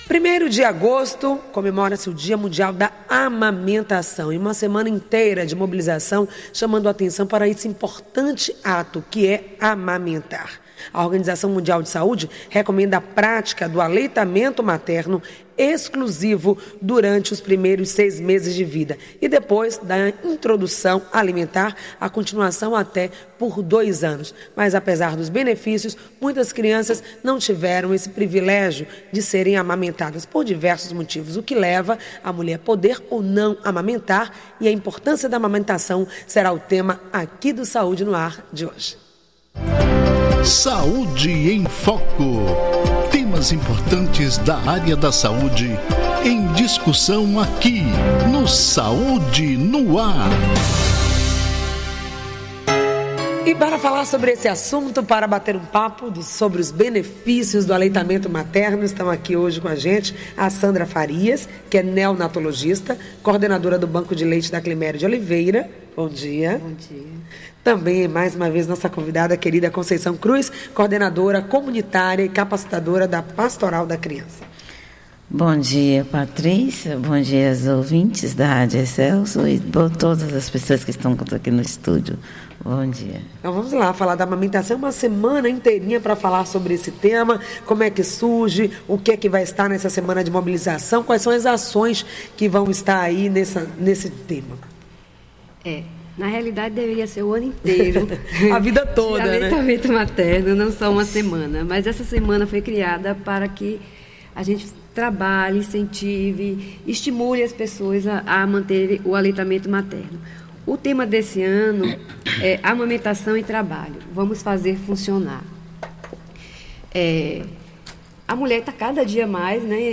Mesa Redondam